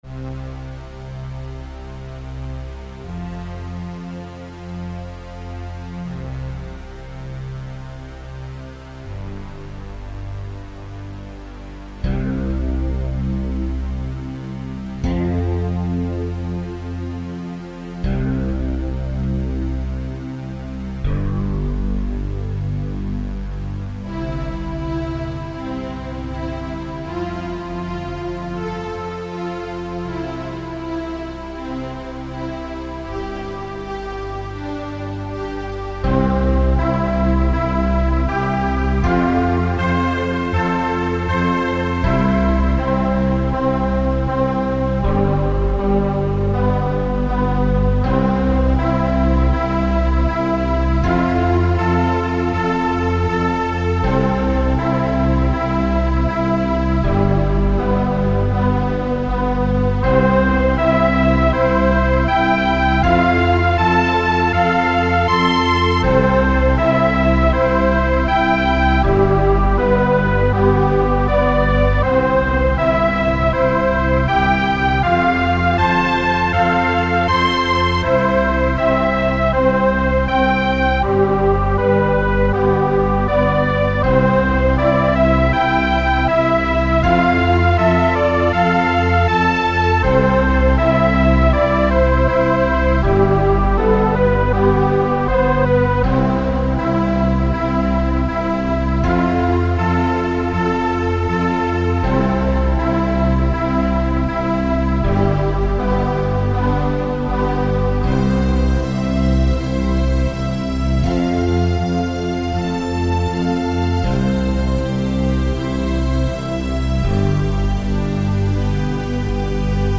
in Fruity Loops. Another attempt to try new sounds that might work together in a song.